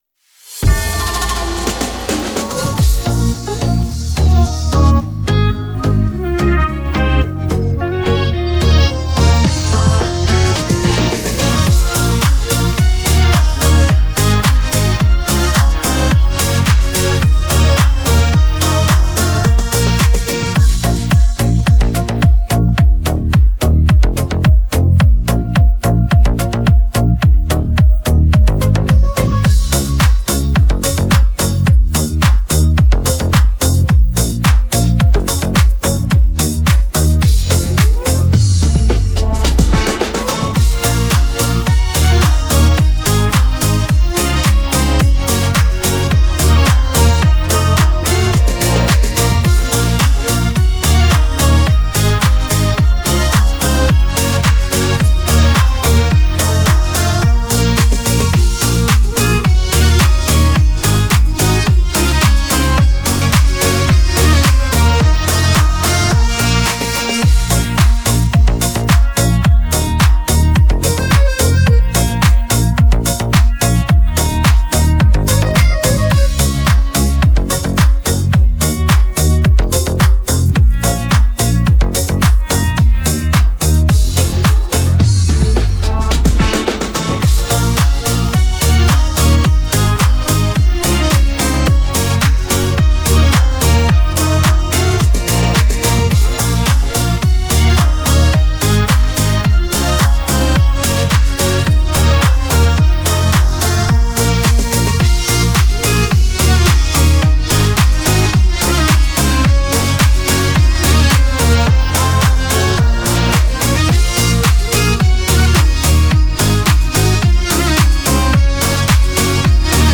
Мощный вокал, сочетание поп-музыки, фолка и романса.
(минус)